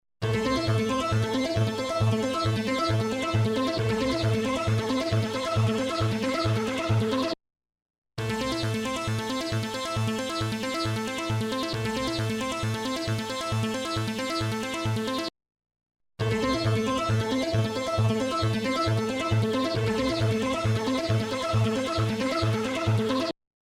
渦巻くクラシック・ロータリーサウンド
Rotary Mod | Synth | Preset: Spaceship Rotary
Rotary-Eventide-Synth-Arpeggio-Spaceship-Rotary.mp3